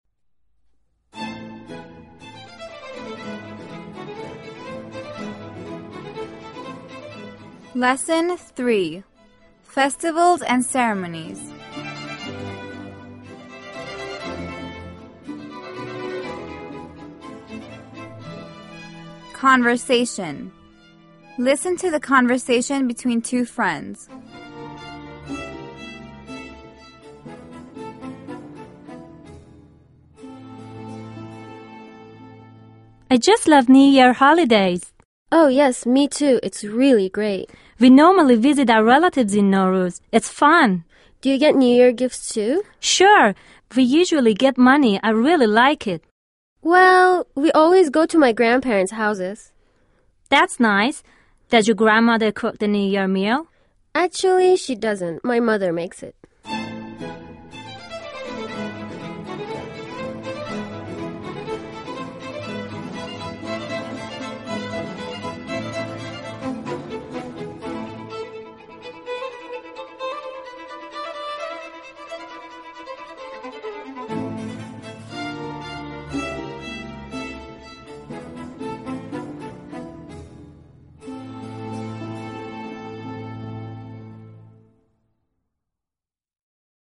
Conversation
conversation.mp3